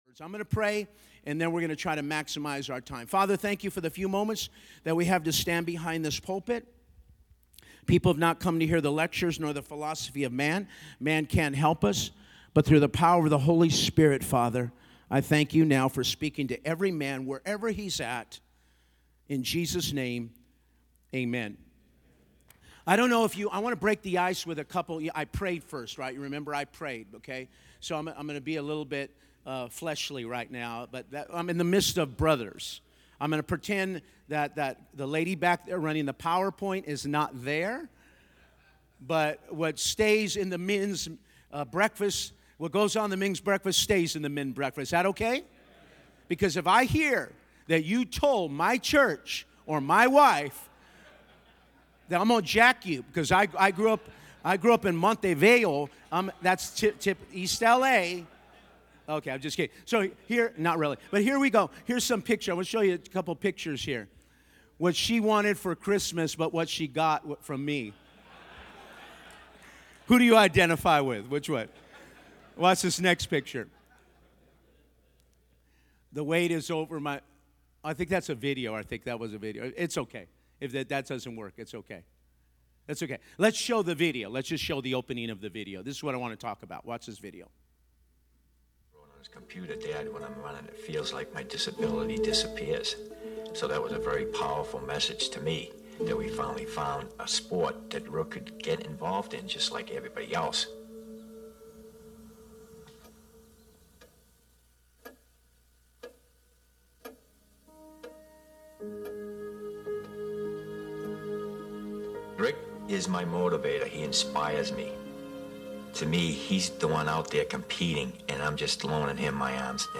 mens-breakfast.mp3